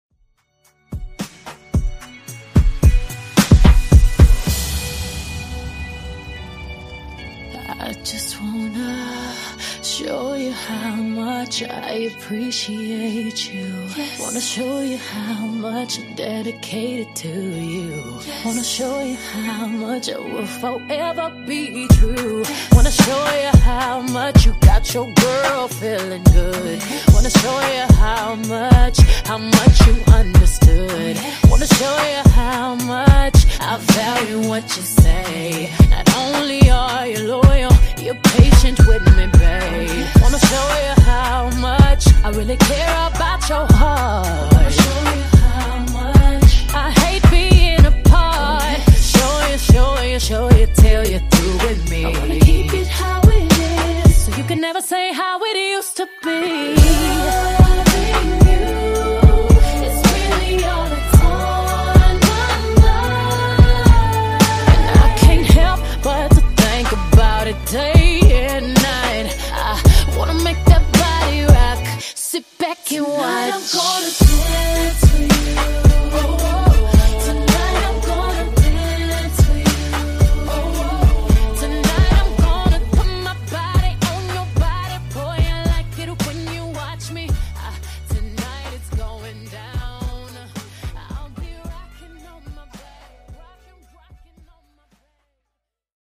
Genre: RE-DRUM
Dirty BPM: 126 Time